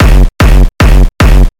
肮脏的踢腿
描述：一个胖胖的踢鼓，有很多超载和一点额外的味道。
Tag: 150 bpm Hardcore Loops Drum Loops 275.80 KB wav Key : Unknown